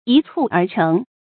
一蹴而成 注音： ㄧ ㄘㄨˋ ㄦˊ ㄔㄥˊ 讀音讀法： 意思解釋： 見「一蹴而就」。